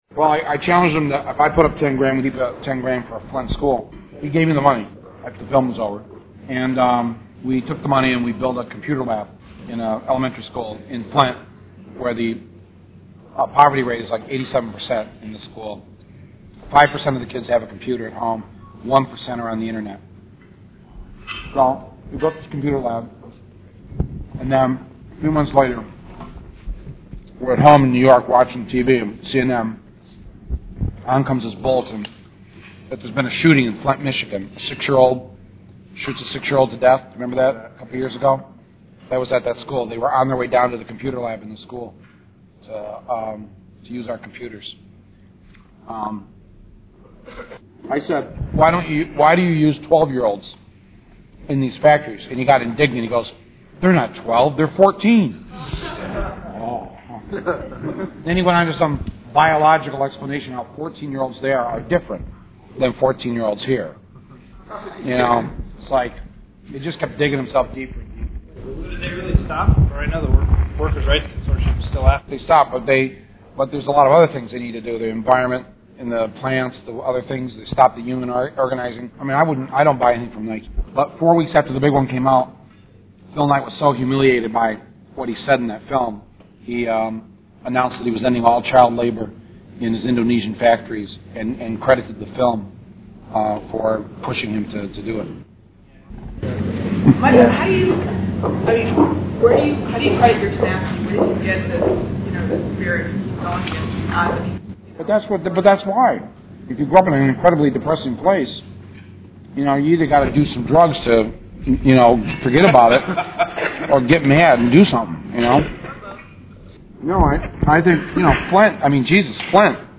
Miscellaneous 042202-mm_afterparty.mp3 (2670 k) Progressive author and filmmaker Michael Moore filled Follinger Auditorium at the U of I and entertained an audience of over 1000 on Apr. 17.